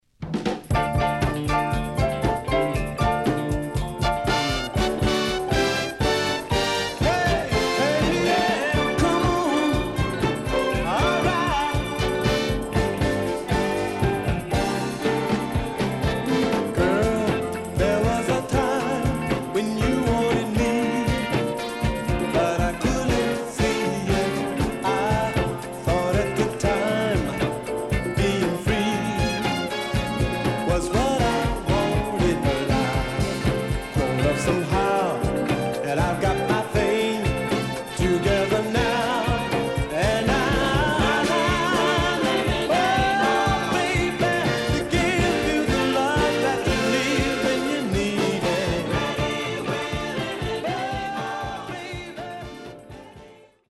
HOME > SOUL / OTHERS